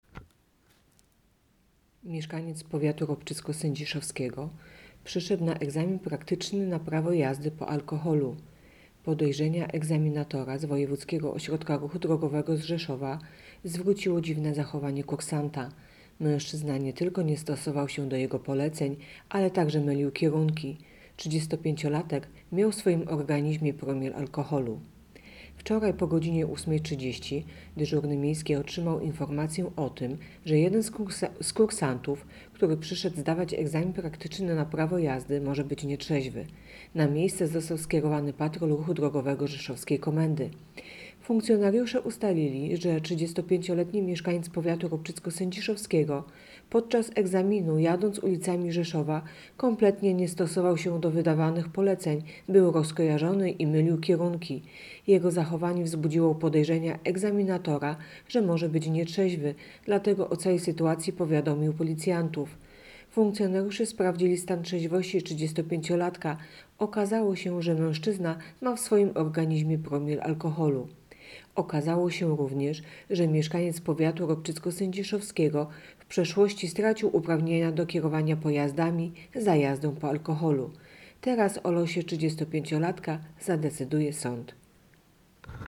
Opis nagrania: Nagranie głosowe informacji pt. Nietrzeźwy 35-latek zdawał egzamin praktyczny na prawo jazdy.